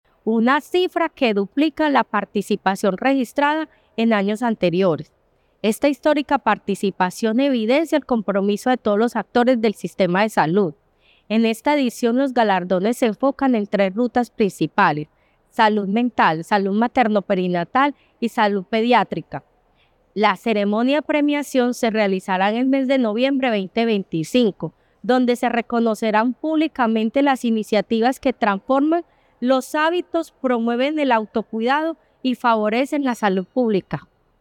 Declaraciones de la subsecretaria de Gestión de Servicios de Salud, Obyalín Morales Medellín reafirma su compromiso con la salud pública y el bienestar de sus comunidades con la histórica participación en los Galardones de Salud: Experiencias que Transforman Vidas 2025.
Declaraciones-de-la-subsecretaria-de-Gestion-de-Servicios-de-Salud-Obyalin-Morales.mp3